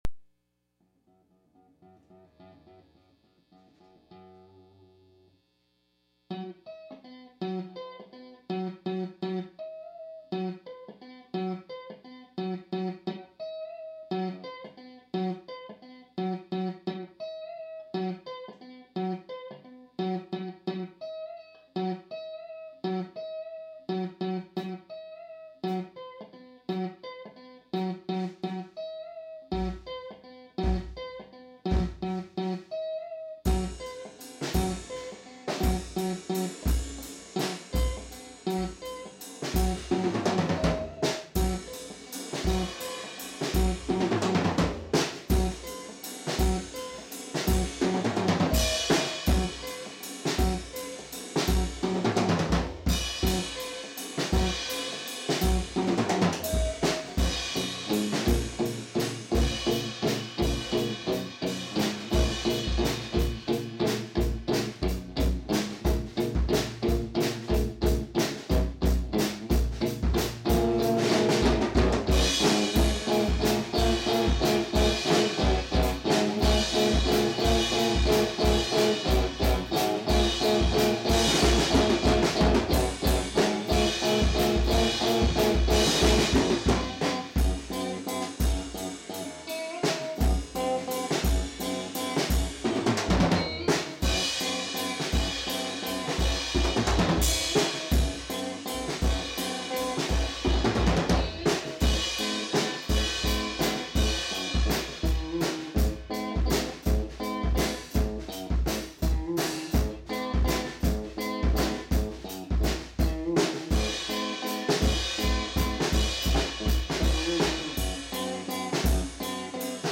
(practice2 take4)